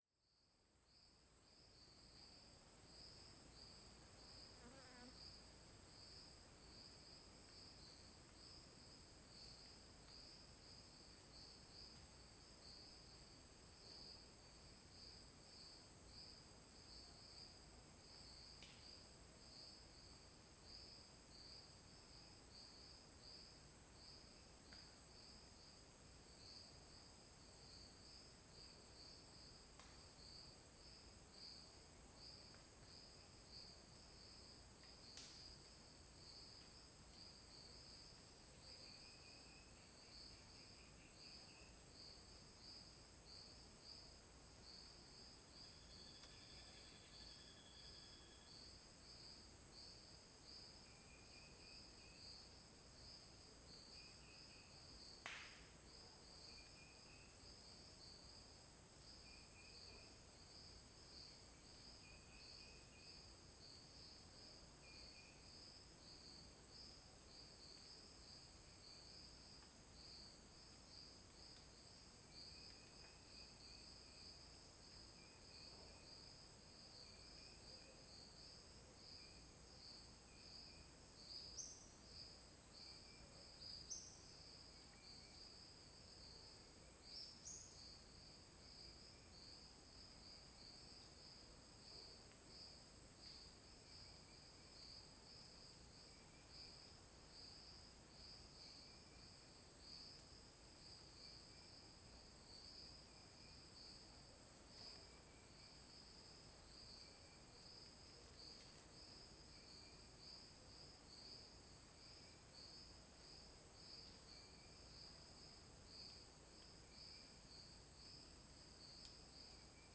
Ambiente na mata fechada no fim de tarde com grilos e estalos de madeira Ambiente externo , Estalos , Floresta , Folhas secas , Grilo , Mata fechada , Tarde Chapada dos Veadeiros Stereo
CSC-05-050-OL- Ambiente na floresta com grilo e estalos de madeira.wav